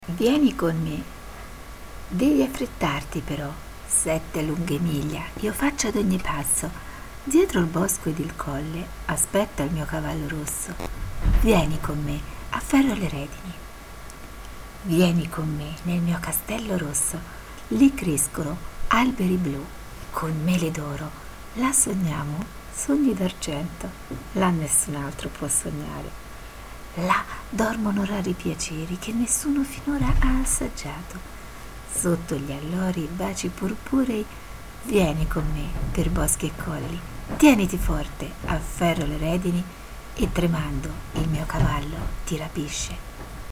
Inserito in Poesie recitate da docenti